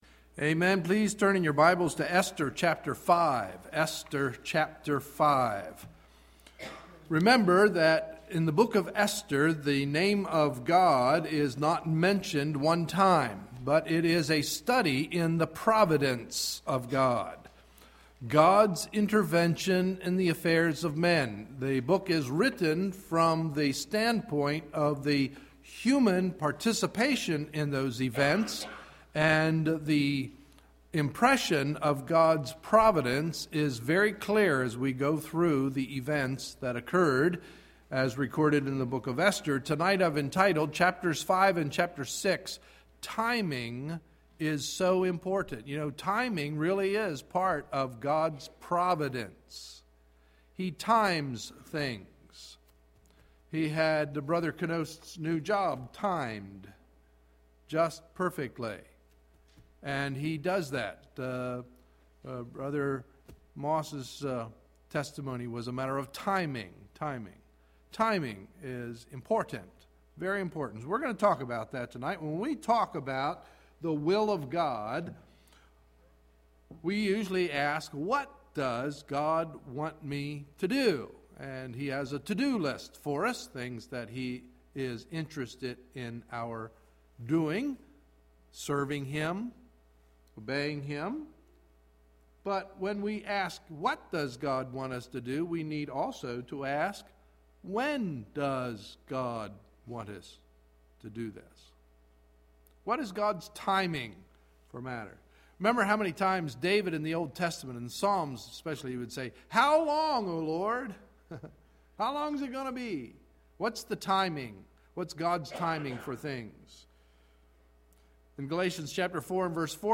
Sunday, April 17, 2011 – Evening Message